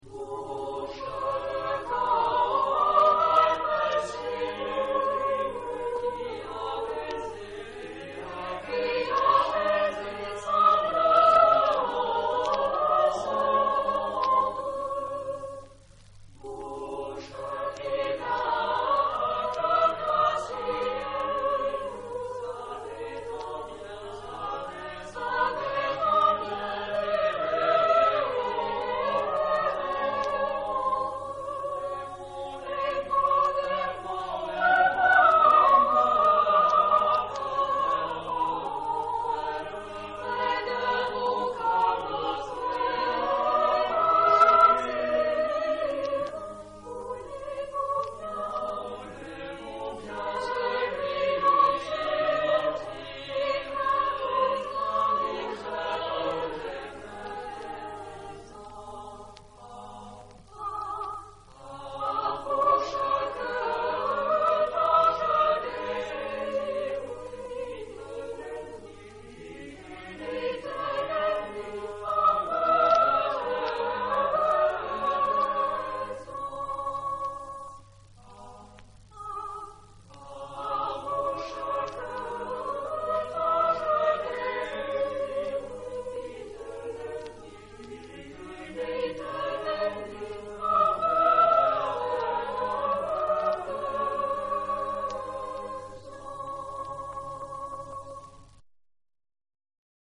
Época : Siglo 16
Género/Estilo/Forma: Renacimiento ; Profano
Tipo de formación coral: SATB  (4 voces Coro mixto )